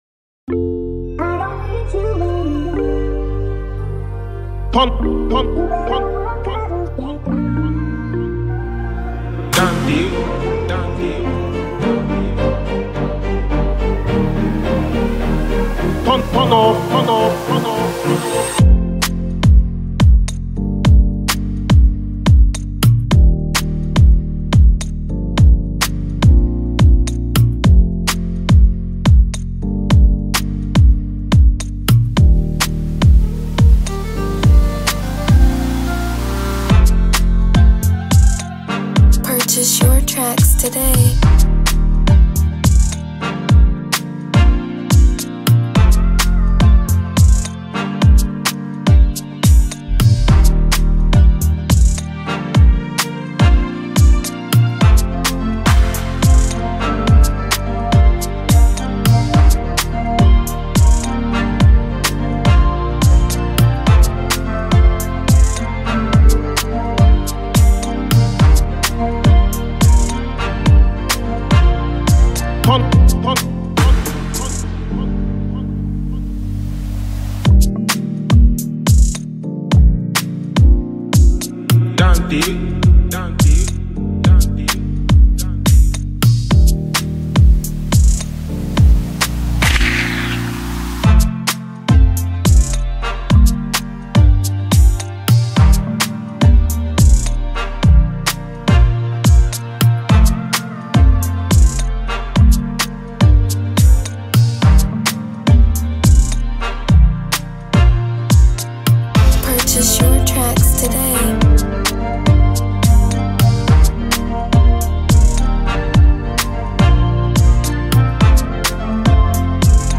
Afropop instrumental type
This Afroswing inspired type of beat